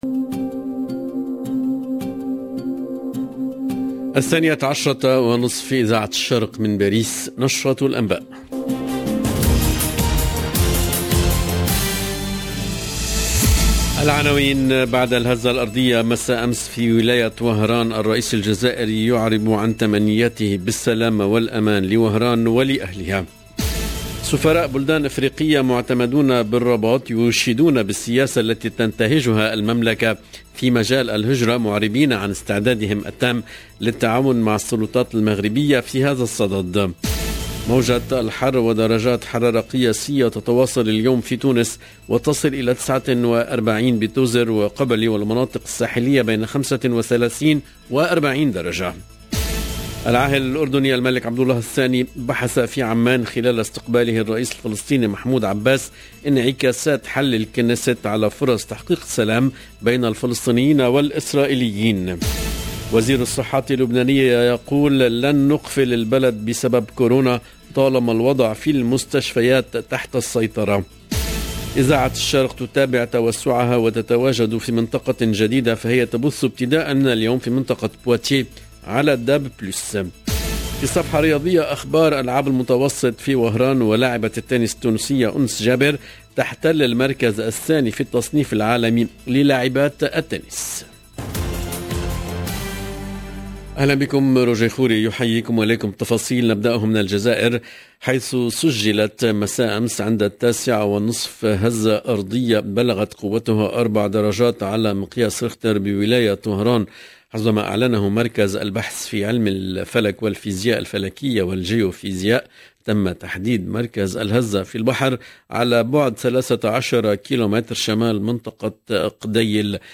LE JOURNAL EN LANGUE ARABE DE MIDI 30 DU 27/06/22